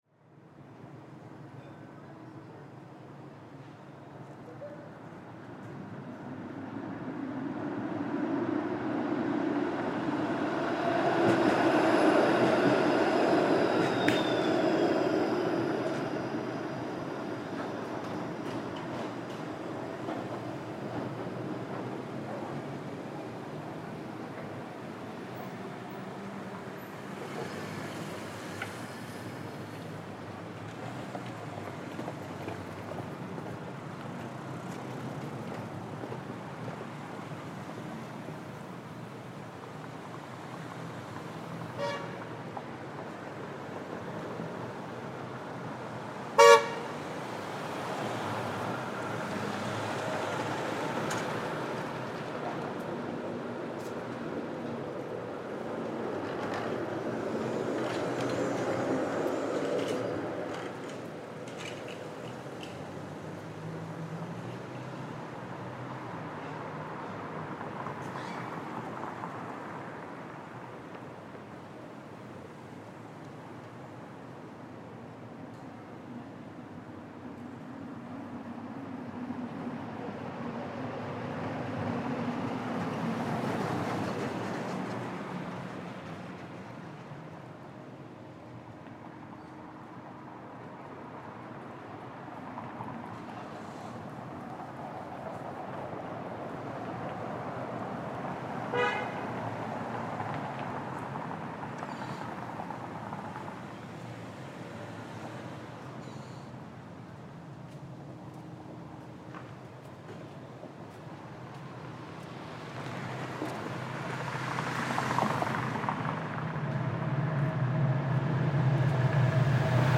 Trams passing by in downtown Toronto, Canada.